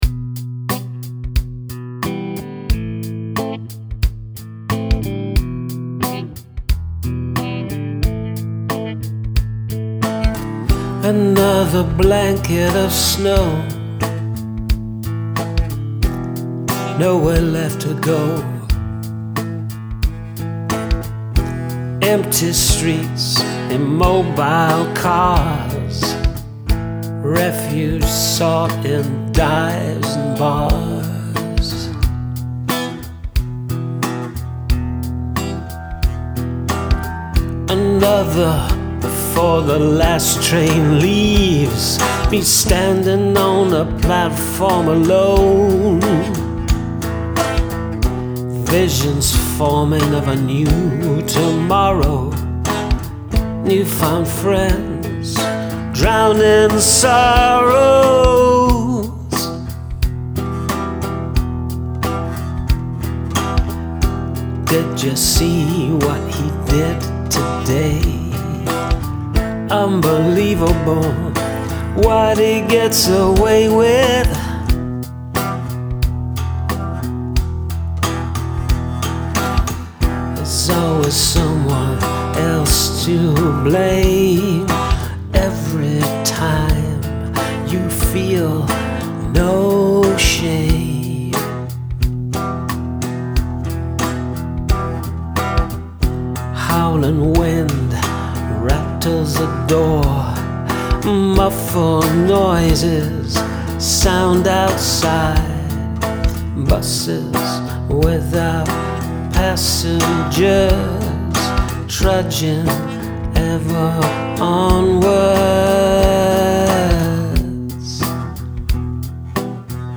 This feels gritty and raw, I love it.
It sounds lost and locked up.